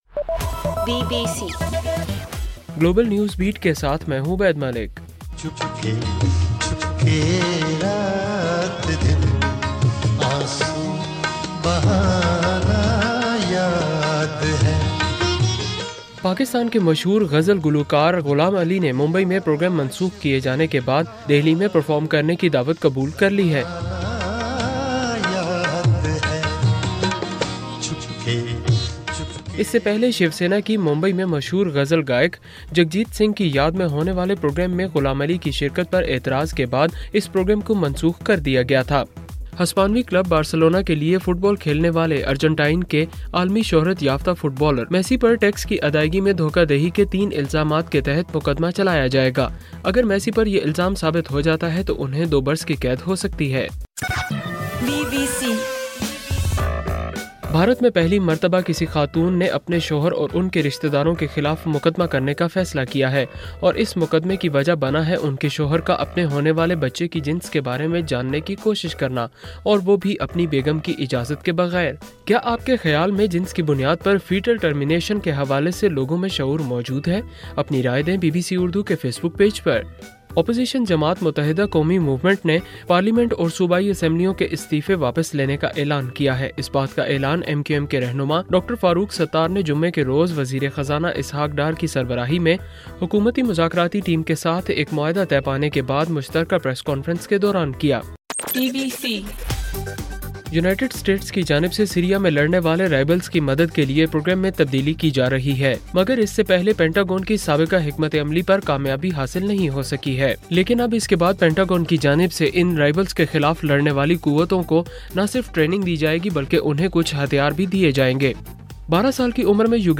اکتوبر 9: رات 12 بجے کا گلوبل نیوز بیٹ بُلیٹن